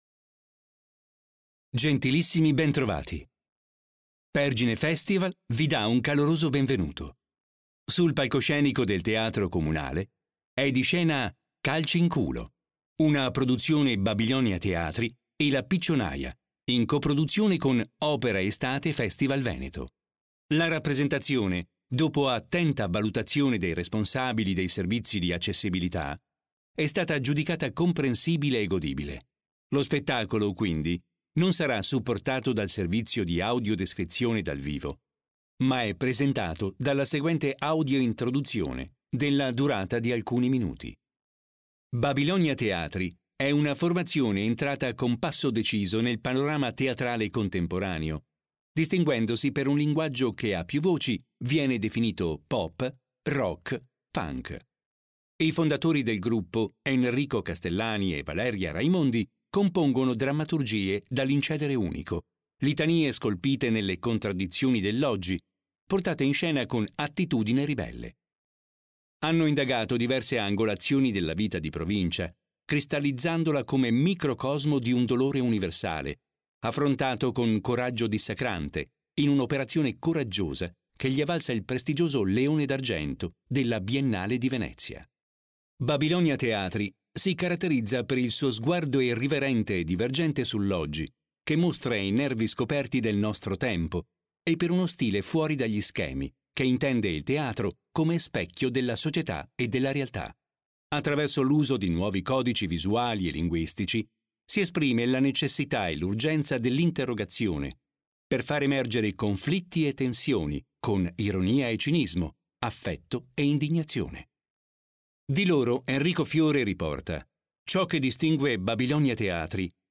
Audio-introduzione per non vedenti